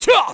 Roman-Vox_Jump_kr.wav